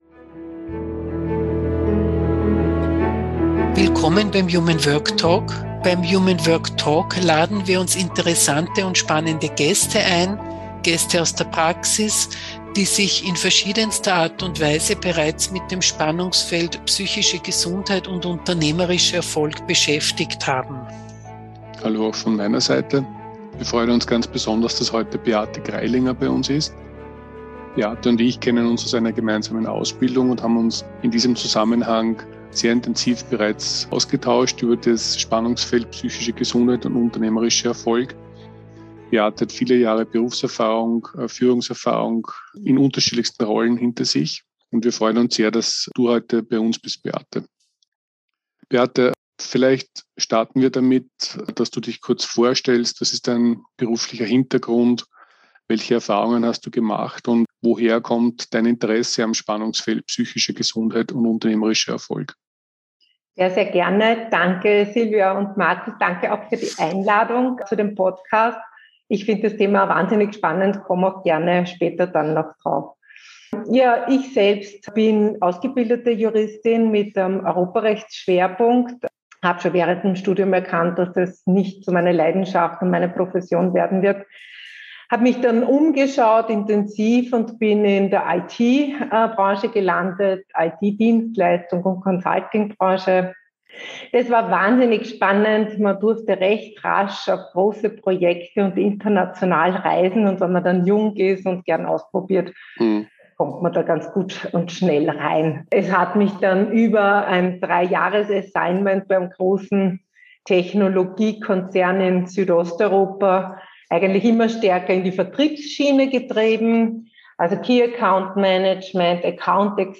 Im human work Talk sprechen wir mit spannenden Gästen über ihre Erfahrungen im Spannungsfeld psychische Gesundheit und unternehmerischer Erfolg.